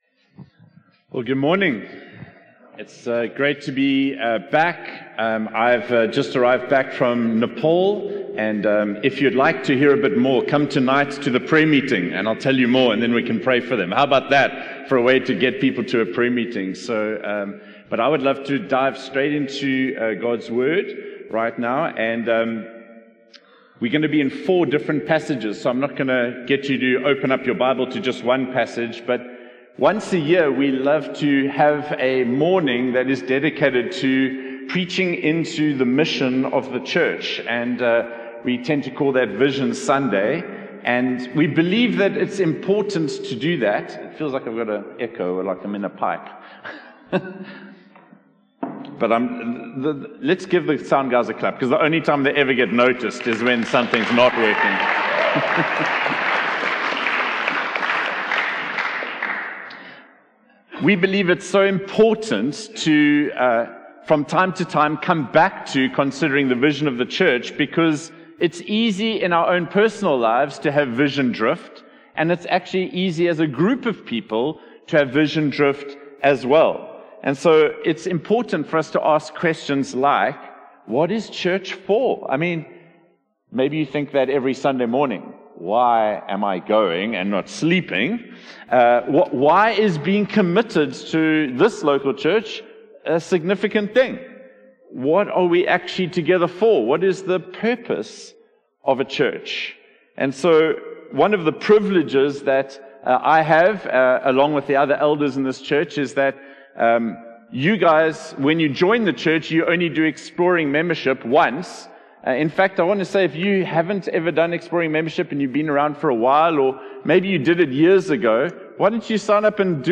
One-Hope-Sermon-8-Feb-2026.mp3